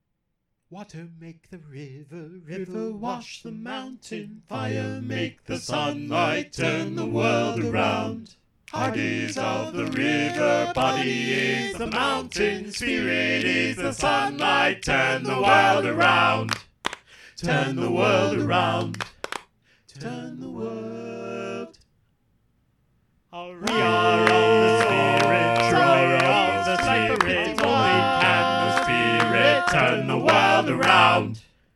Key written in: F Major
How many parts: 4
Type: Barbershop
All Parts mix: